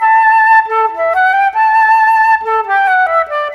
Rock-Pop 01 Flute 04.wav